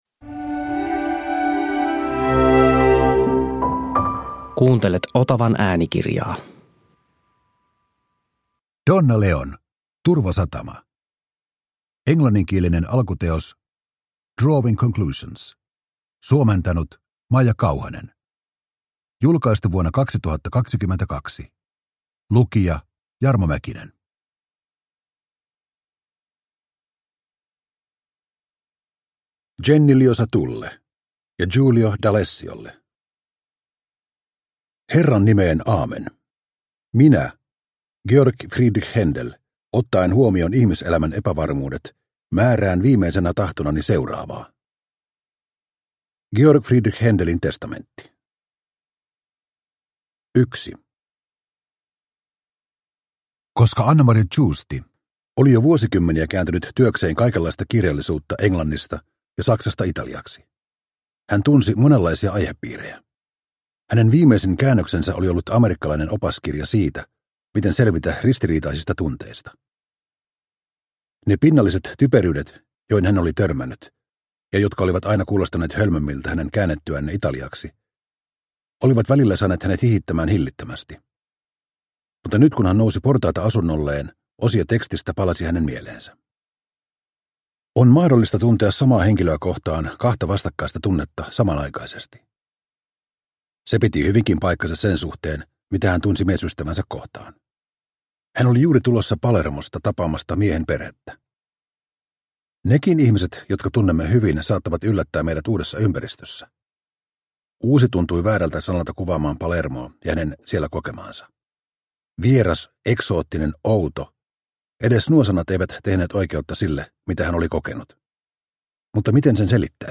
Turvasatama – Ljudbok – Laddas ner